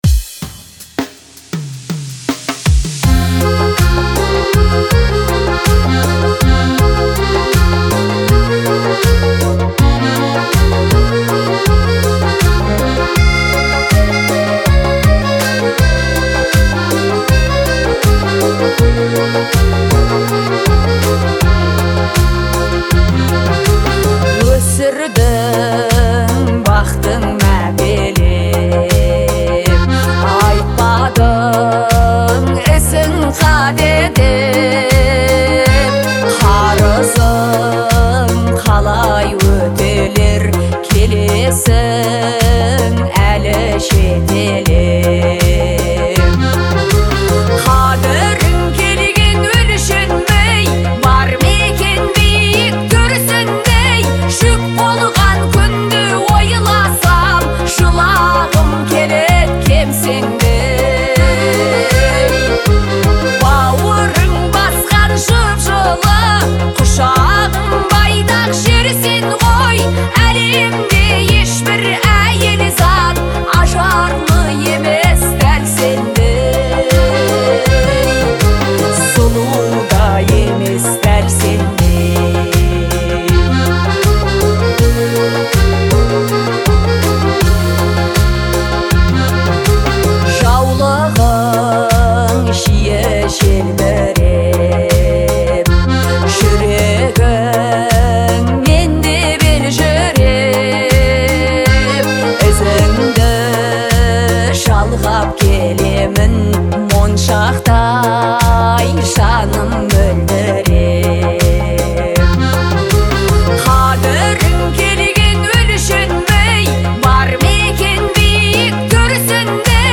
уникальным голосом и эмоциональной подачей